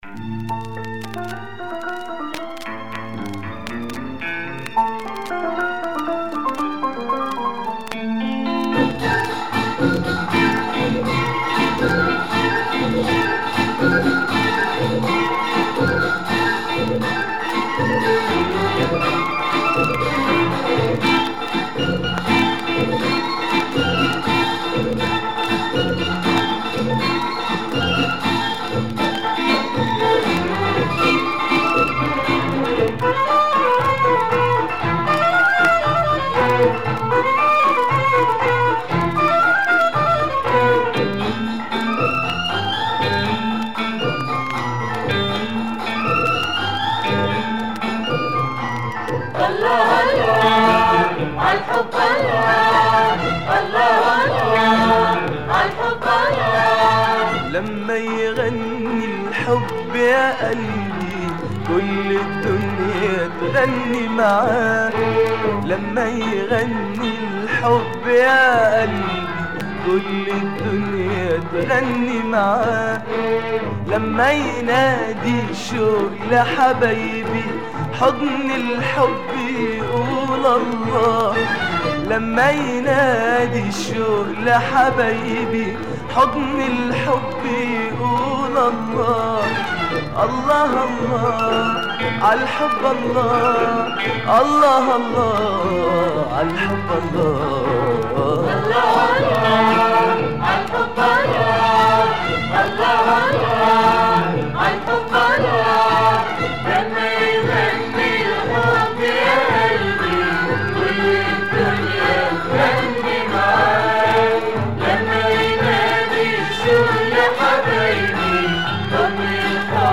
Egyptian singer
early 70’s oriental beats with psych organ.